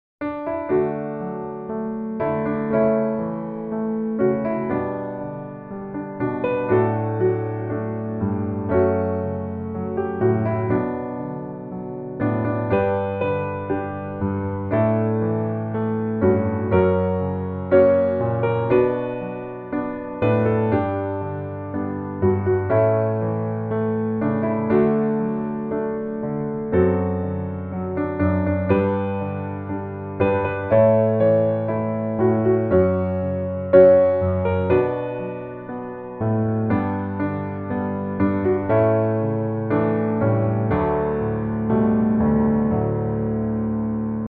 D Major